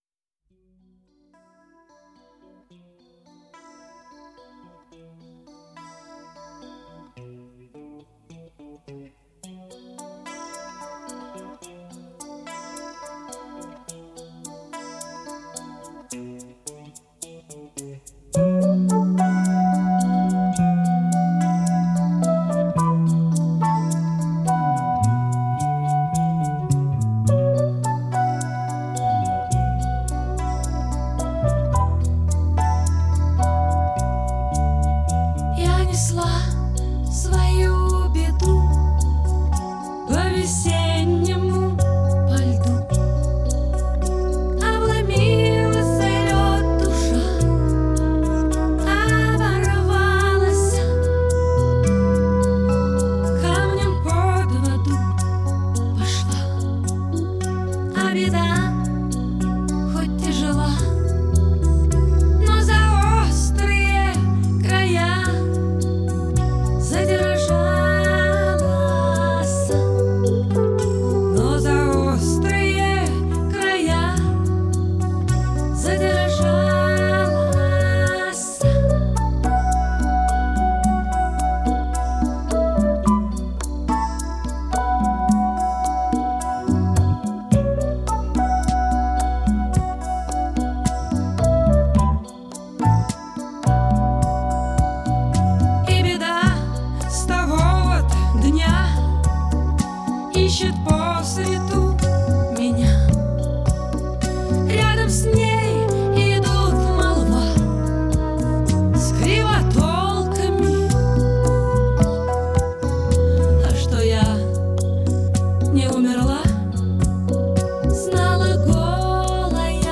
студийная версия 1980